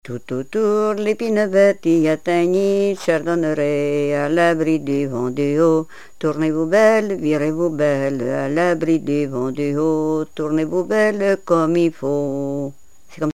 branle : courante, maraîchine
Répertoire de chansons traditionnelles et populaires
Pièce musicale inédite